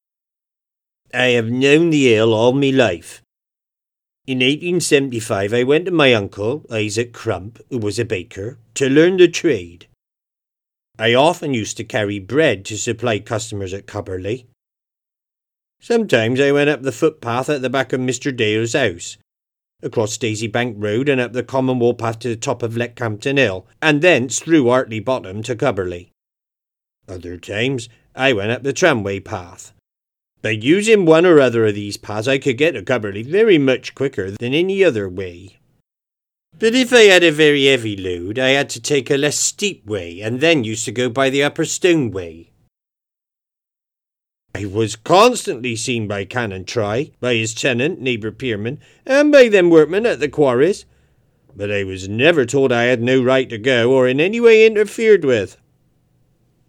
Regional and Foreign Accents